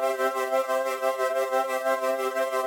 SaS_MovingPad04_90-C.wav